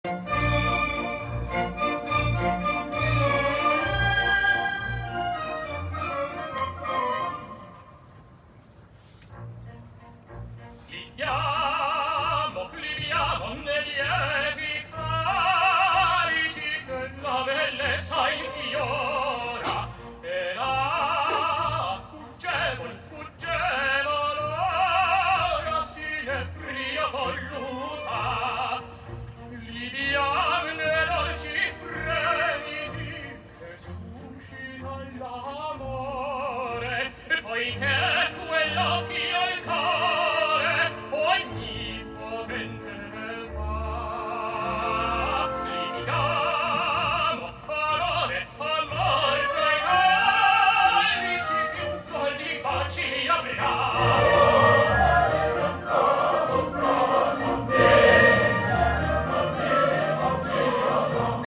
at the Rome Opera